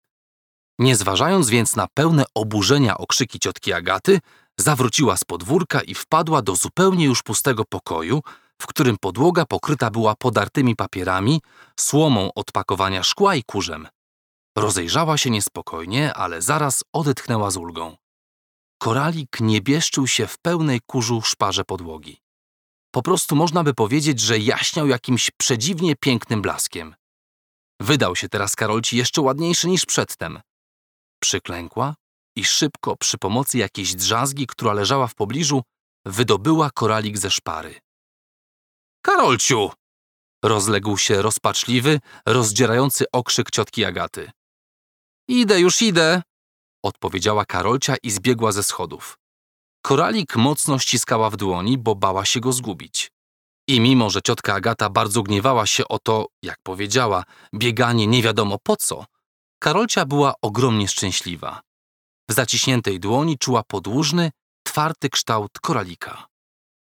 Celebrity Male 30-50 lat
Sports journalist and voice artist who excels in film narrations as well as commercials and audiobooks.
Nagranie lektorskie